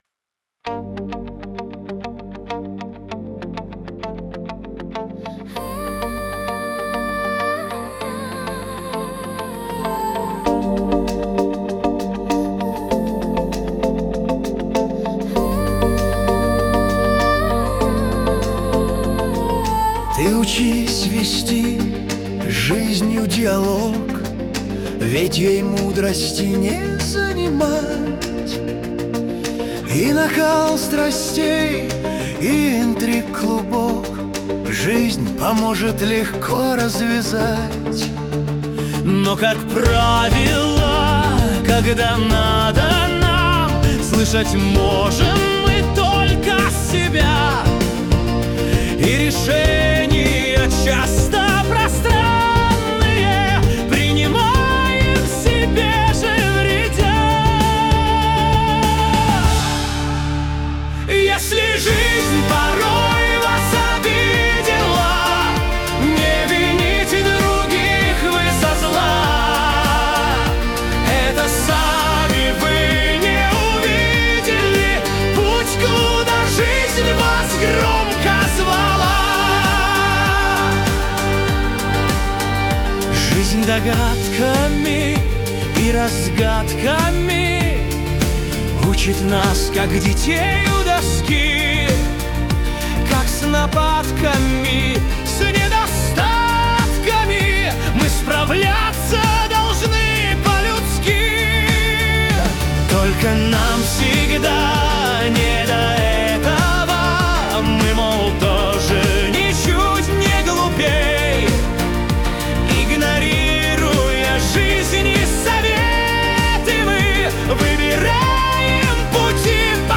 кавер-версия
Для Медитаций